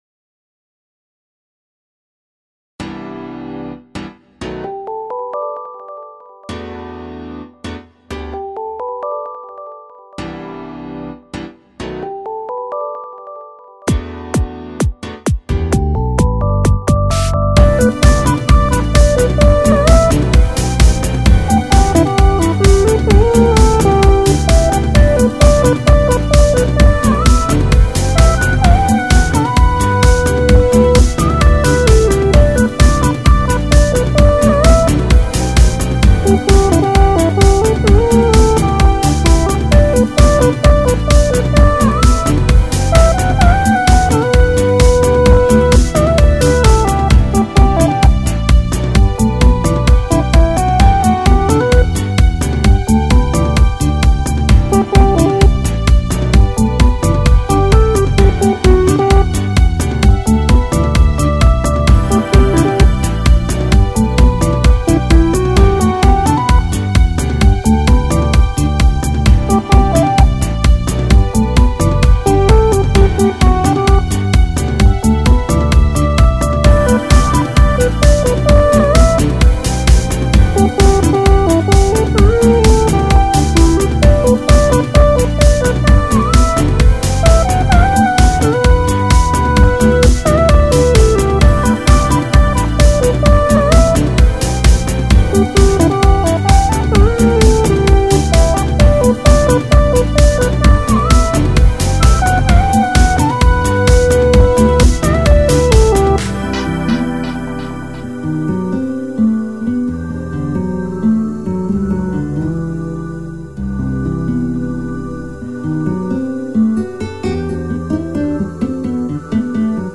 ポップロング明るい